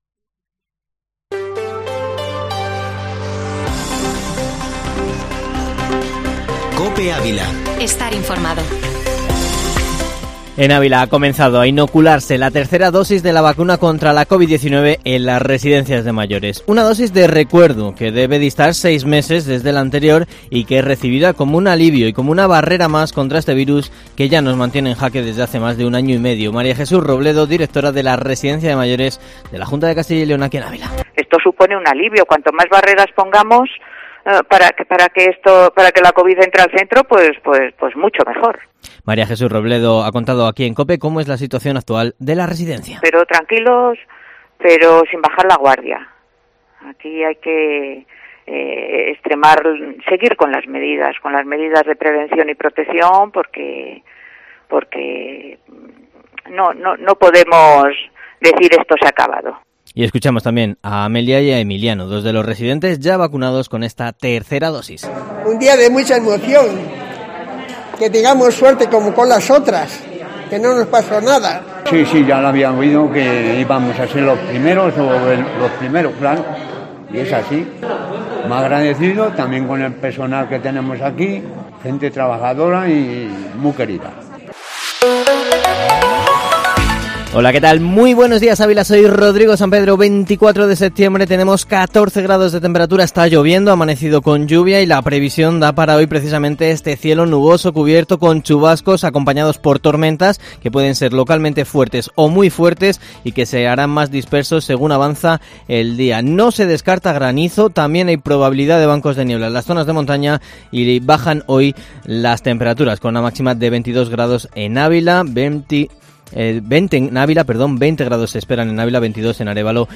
Ávila